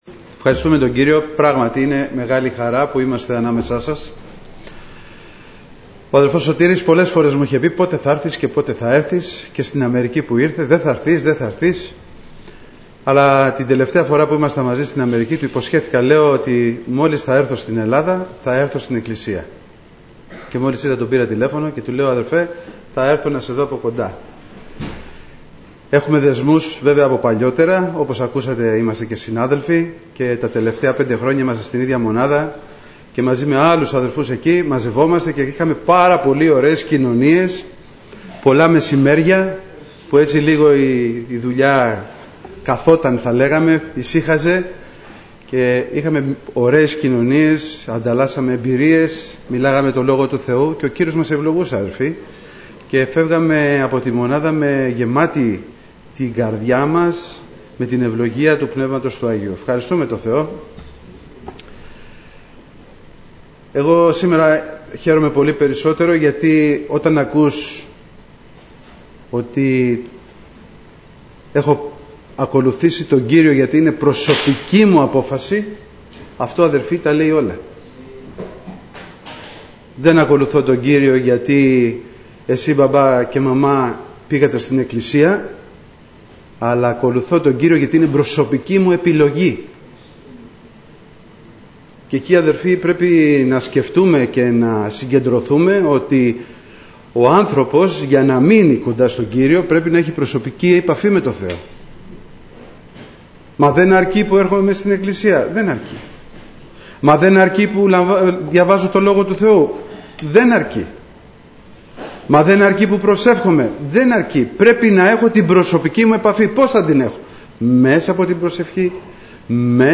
Στο Αρχείο Κηρυγμάτων θα βρείτε τα τελευταία Κηρύγματα, Μαθήματα , Μηνύματα Ευαγγελίου που έγιναν στην Ελευθέρα Αποστολική Εκκλησία Πεντηκοστής Αγίας Παρασκευής
Σειρά: Κηρύγματα